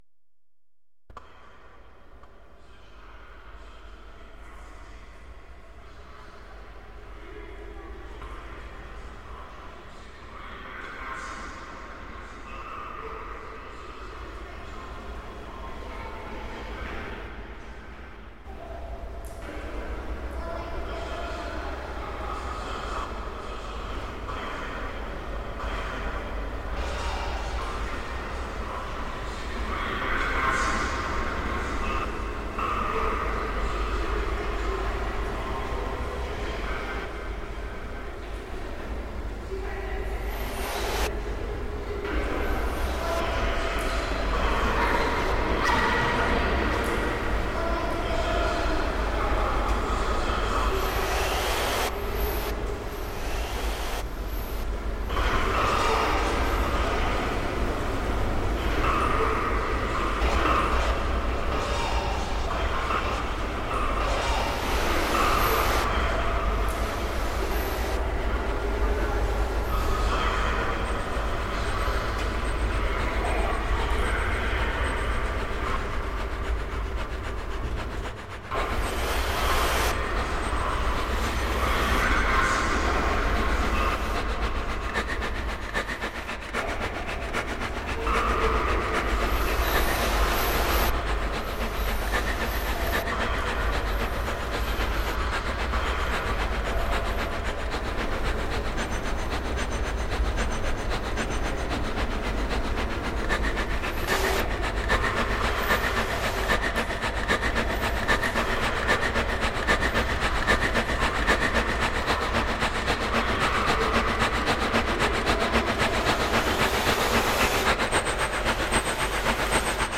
I'm still struggling with a laptop free creative set up but stuck to my core principle of using only original source sounds to complete the finished piece. The sample was processed using 2 iPad apps (Samplr and Borderlands) and an OP1, with composition and further processing done in Logic. The results ended up being rather scary so I titled the song after the most terrifying thing to be experienced at a train station.